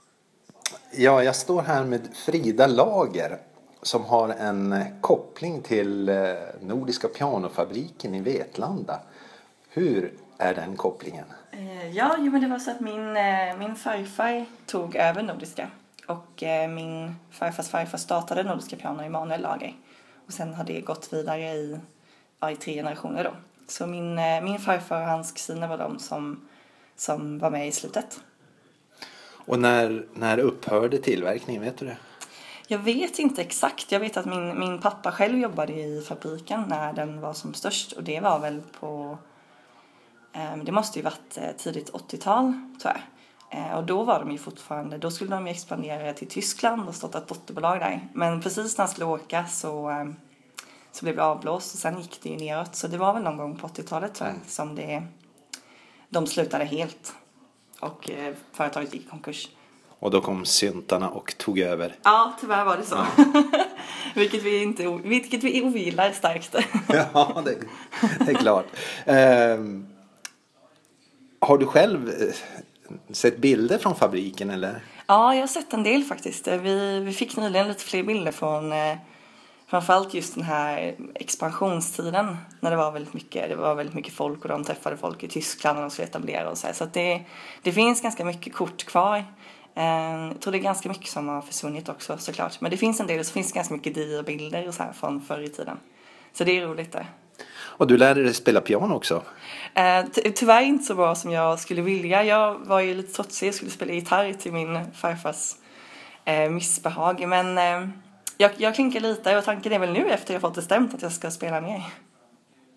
Kuriosa: Intervju med släkting till pianotillverkaren Nordiska pianofabriken